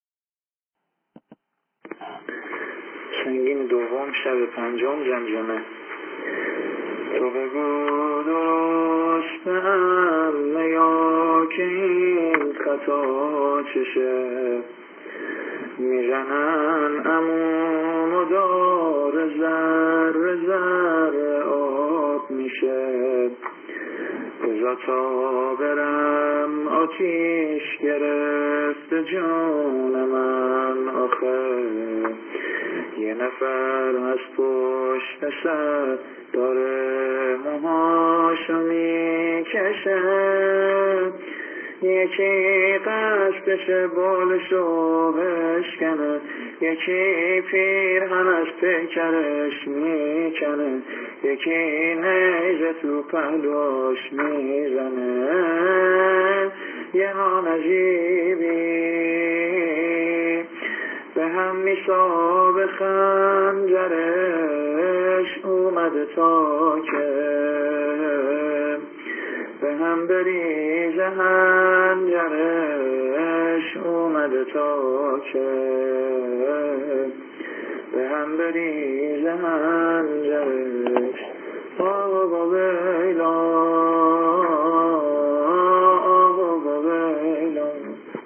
اشعار شب پنجم به همراه سبک/سنگین -( تو بگو درسته عمه یا که این خطا چشه )